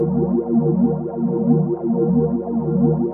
lifeloop.wav